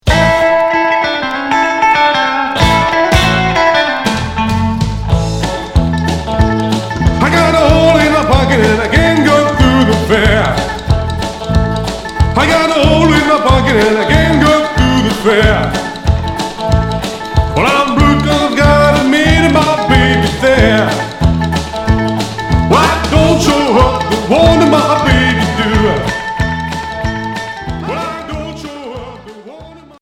Rockabilly Unique 45t retour à l'accueil